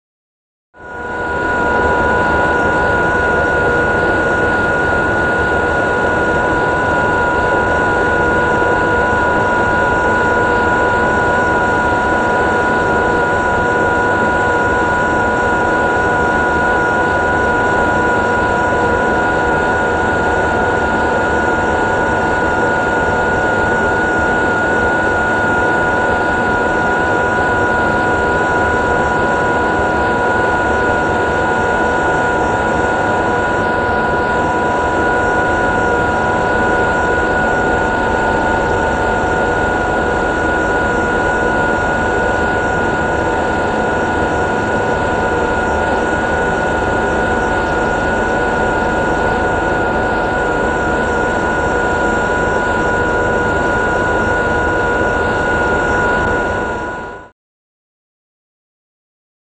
Generator; Constant; Generator Hum Constant.